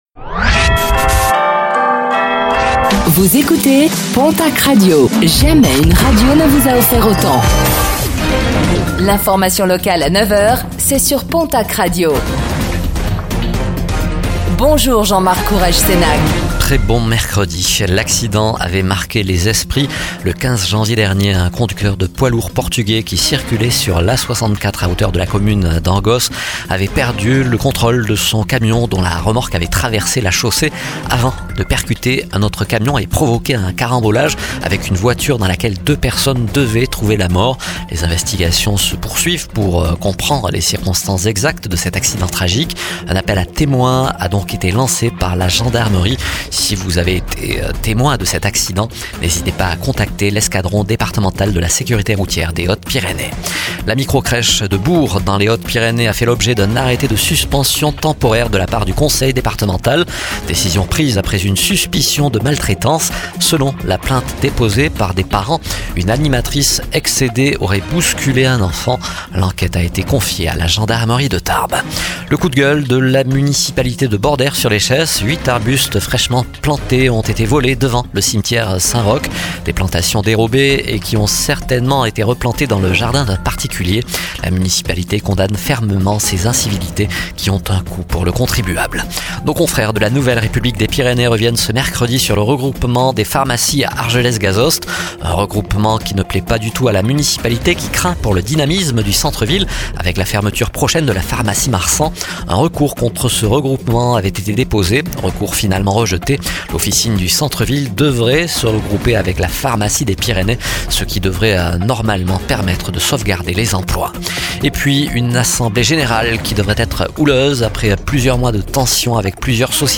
09:05 Écouter le podcast Télécharger le podcast Réécoutez le flash d'information locale de ce mercredi 19 mars 2025